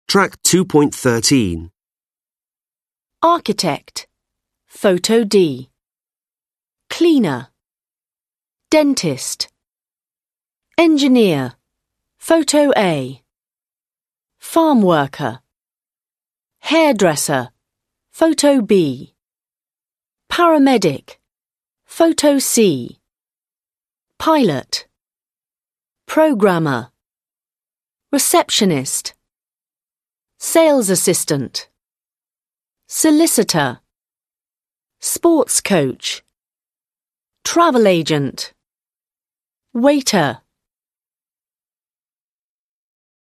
Match four of the words below with photos A-D. Then listen to the wordlist and underline the stress.